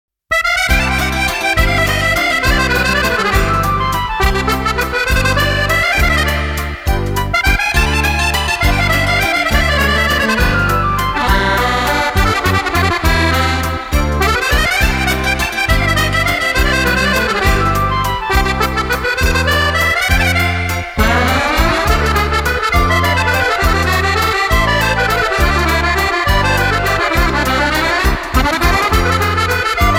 Genre : valse.